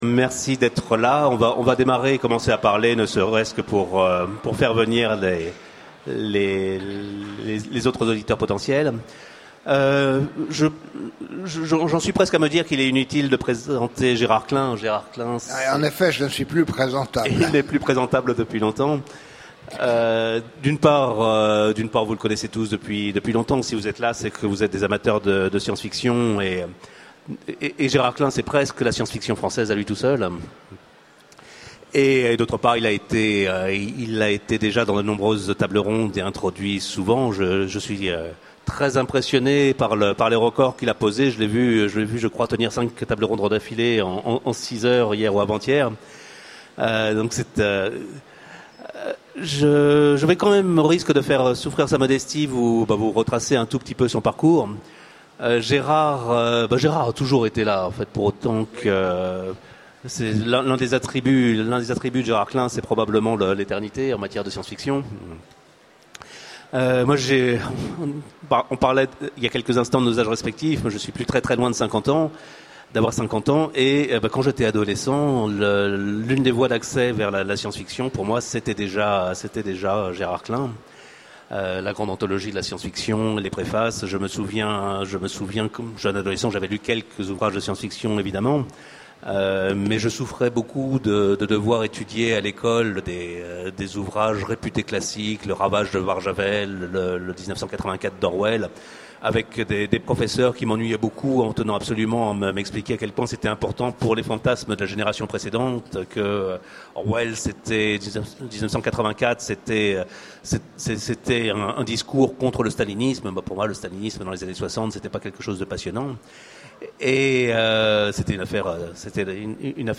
Une interview de Gérard Klein aux Utopiales 2012, redécouverte à l'occasion des 20 ans d'Actusf en 2020
Utopiales 12 : Conférence Rencontre avec Gérard Klein